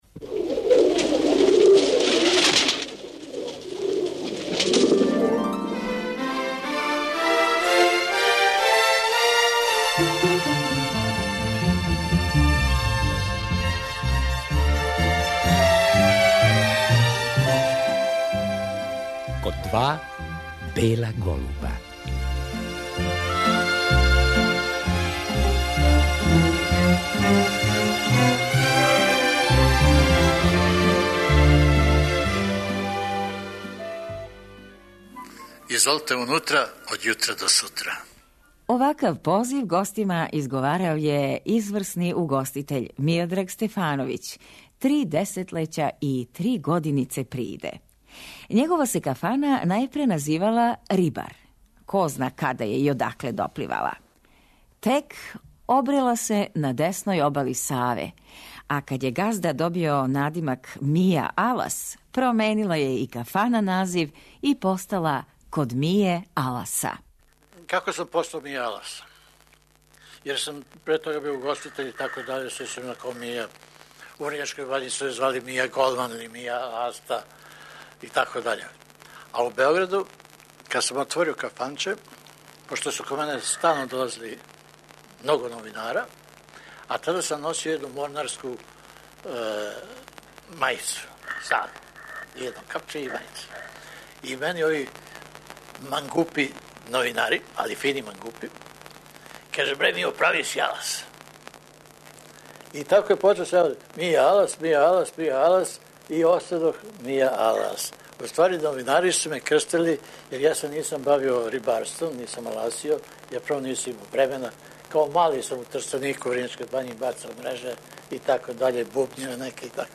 Чућемо и снимак са једног дочека нове године у коме је главну реч имао Слободан Марковић, песник и сликар, иначе неизоставни гост кафане "Код Мије Аласа".